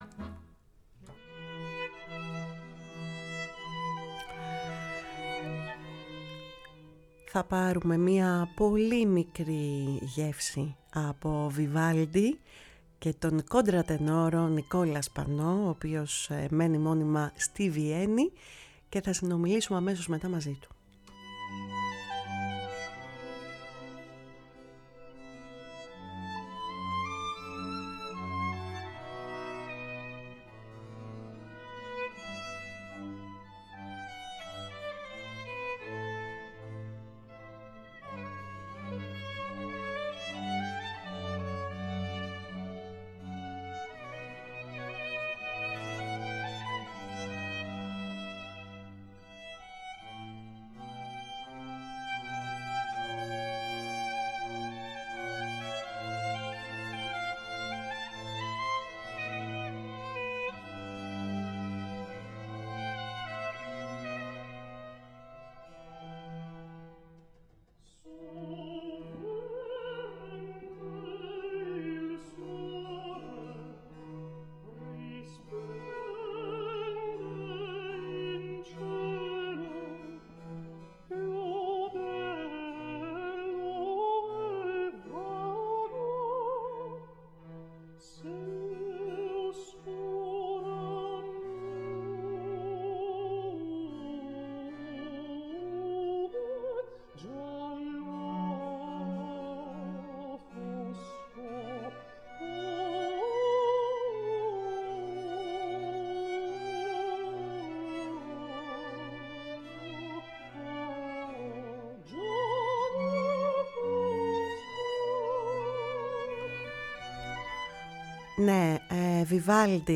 Κουβεντες Μακρινες ΣΥΝΕΝΤΕΥΞΕΙΣ